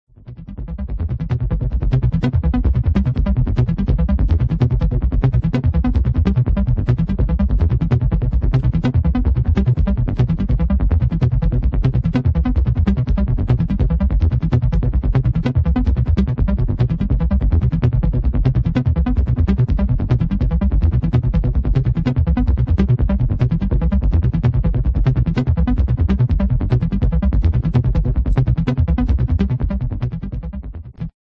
Hard dancefloor killer!
Techno Acid Dub Techno